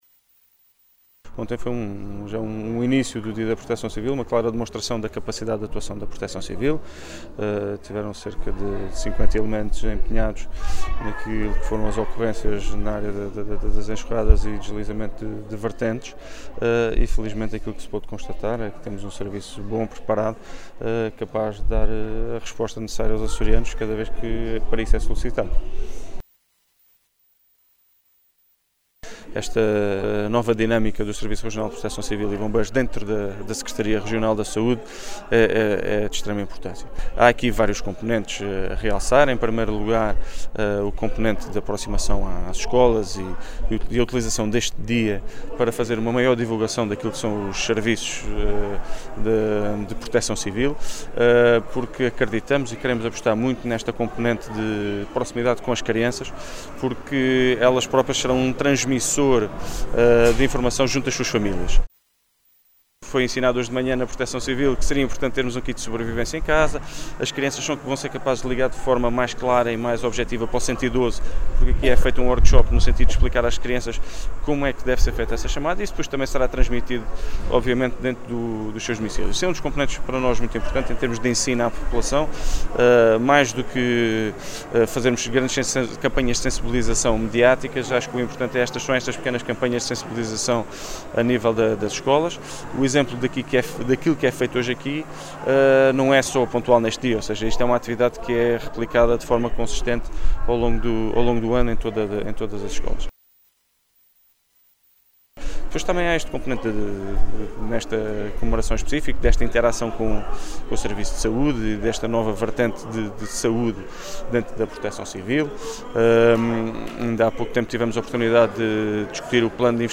“Foi uma clara demonstração da capacidade do Serviço de Proteção Civil e Bombeiros dos Açores, mostrando que é capaz de dar resposta aos açorianos cada vez que é solicitado”, disse Luís Cabral, em declarações aos jornalistas em Angra do Heroísmo, à margem das comemorações do Dia Mundial da Proteção Civil.